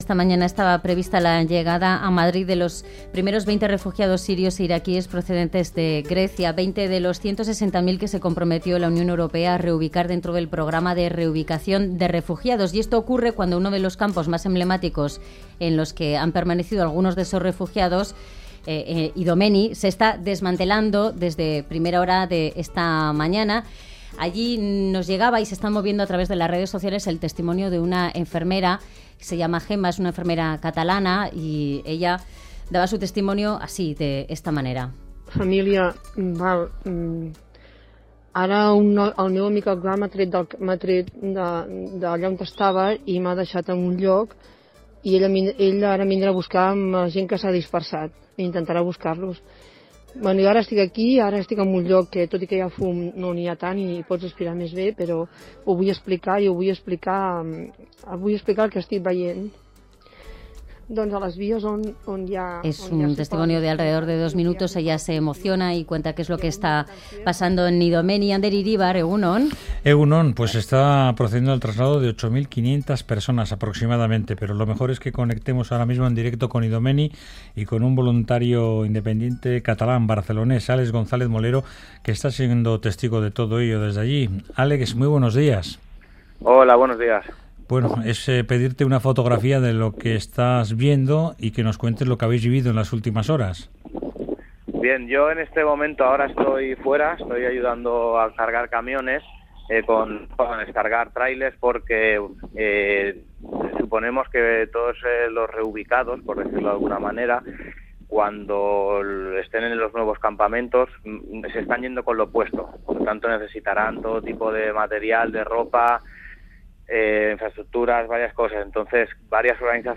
Testimonios desde Idomeni en plena evacuación de 8.500 personas.
La senadora Idoia Villanueva, acaba de regresar de allí.